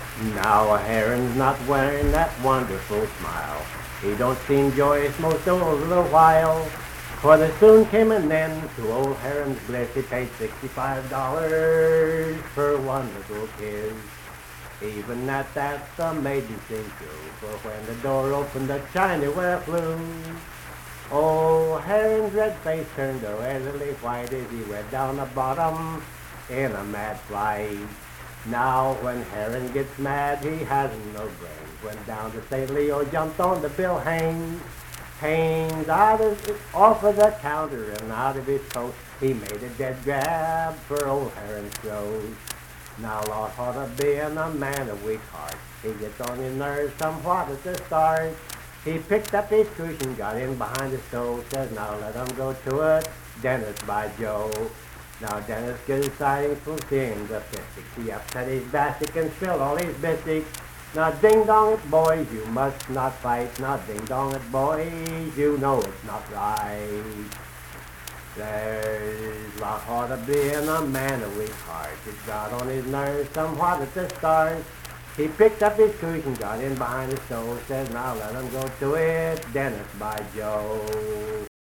Reber's Song - West Virginia Folk Music | WVU Libraries
Unaccompanied vocal music
Verse-refrain 6(4). Performed in Hundred, Wetzel County, WV.
Voice (sung)